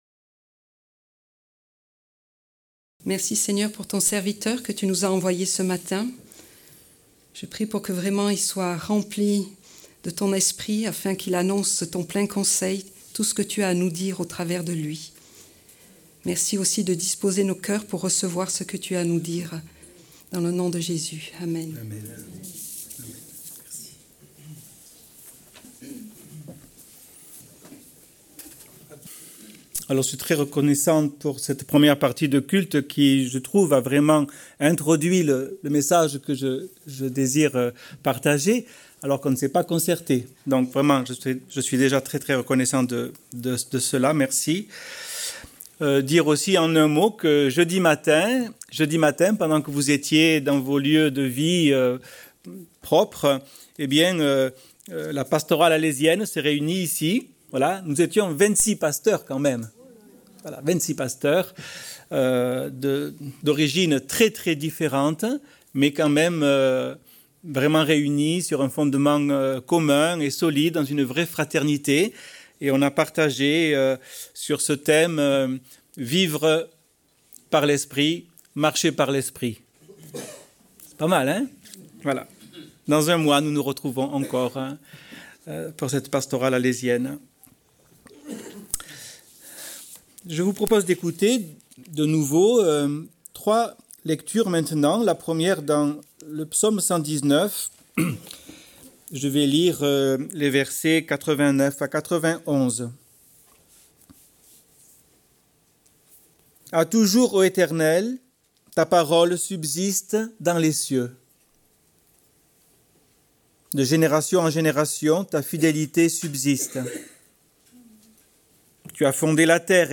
Prédication du 13 mars 2025.